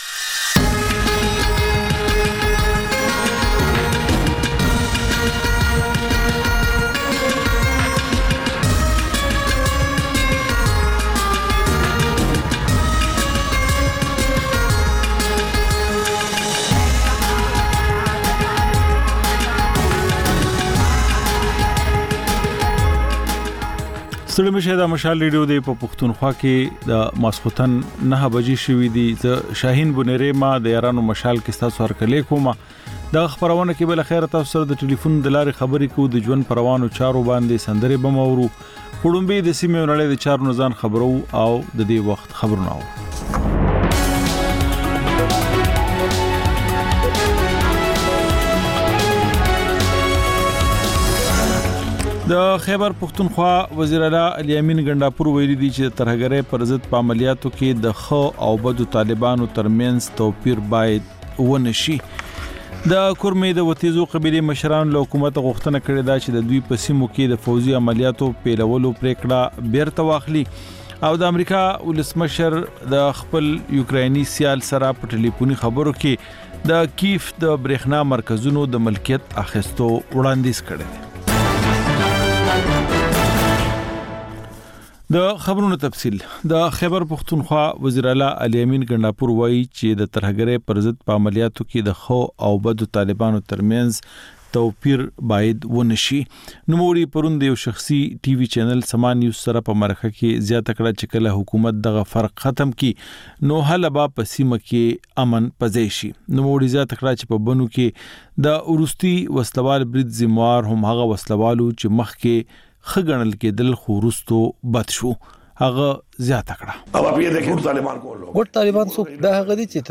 د یارانو مشال په ژوندۍ خپرونه کې له اورېدونکو سره بنډار لرو او سندرې خپروو. دا یو ساعته خپرونه هره ورځ د پېښور پر وخت د ماخوستن له نهو او د کابل پر اته نیمو بجو خپرېږي.